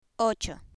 Click on the image to hear the pronunciation of numbers in Spanish 1-20.